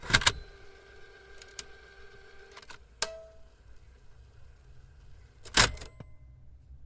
Descarga de Sonidos mp3 Gratis: casette.
tape-deck.mp3